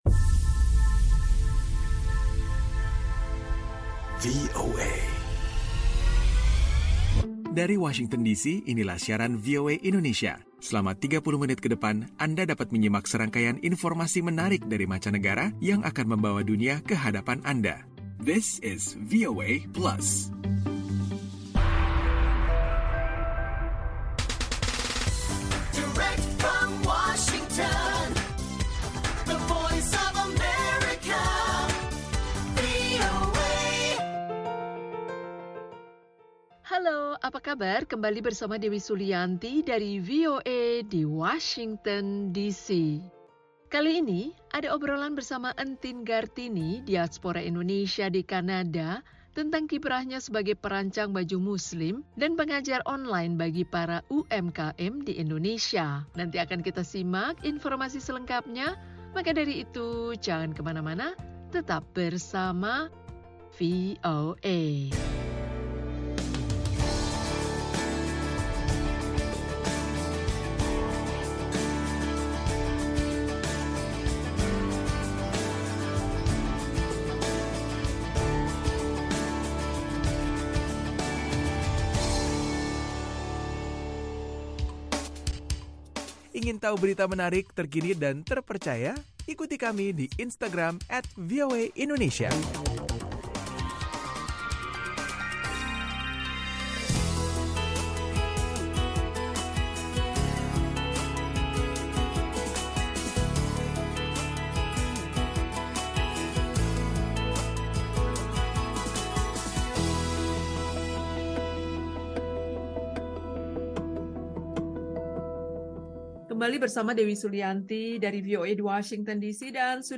VOA Plus kali ini akan mengajak anda menyimak obrolan bersama seorang Diaspora Indonesia di Kanada seputar pekerjaannya sebagai desainer busana Muslim dan mengajar para pengusaha/pemilik UMKM secara daring. Ada pula info tentang larangan penggunaan gas tertawa untuk rekreasi di Inggris.